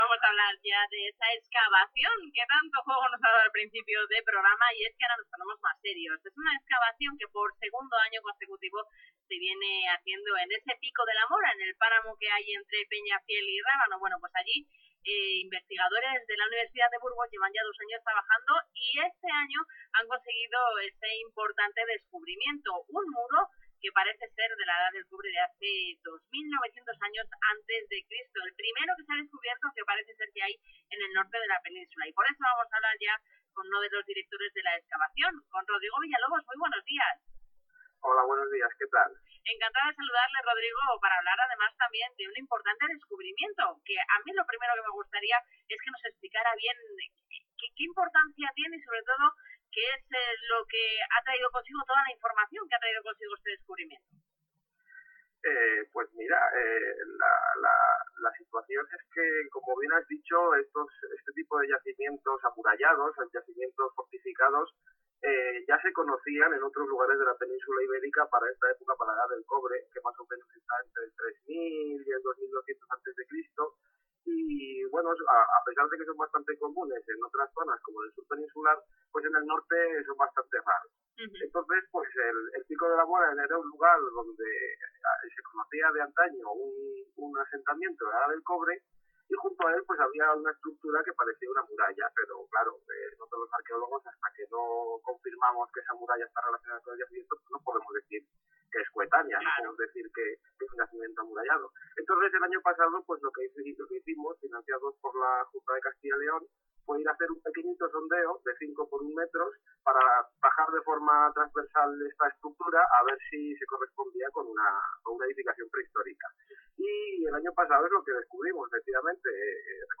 Una nueva entrevista, en esta ocasión para COPE Valladolid: